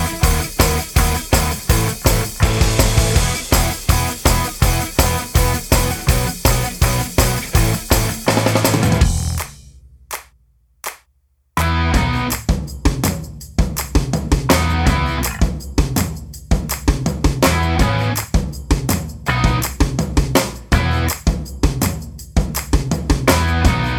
Minus All Guitars For Guitarists 3:09 Buy £1.50